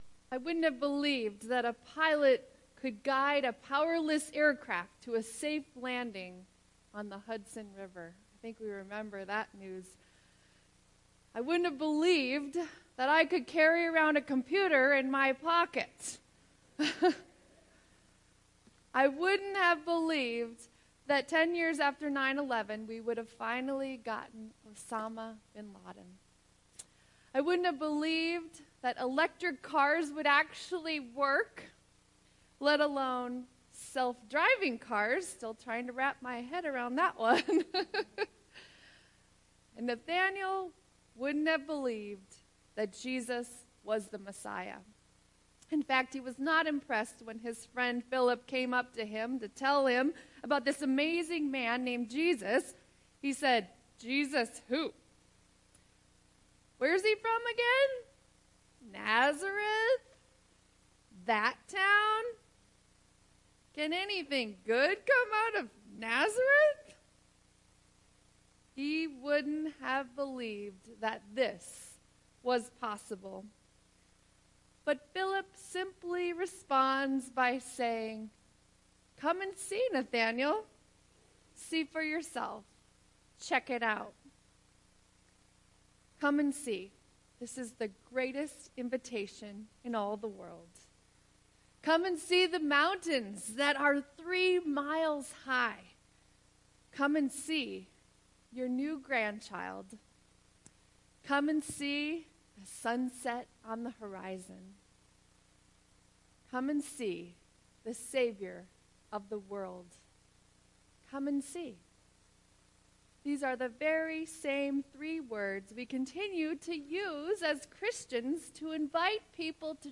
Sermon 1.14.2018